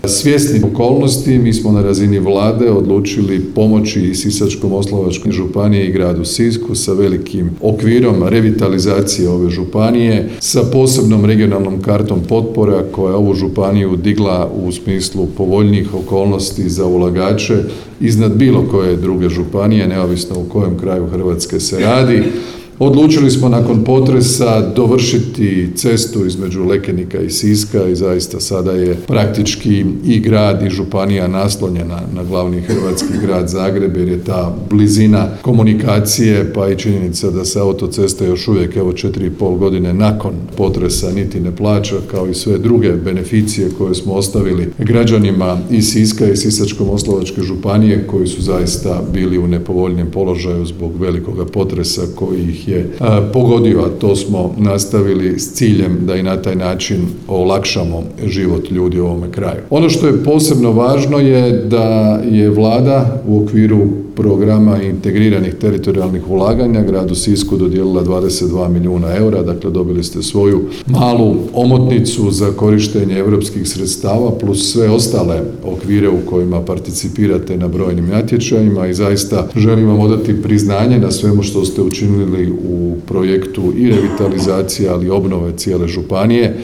Tim povodom u srijedu, 4. lipnja 2025. godine, održana je svečana sjednica Gradskog vijeća Grada Siska.
Premijer Plenković dodaje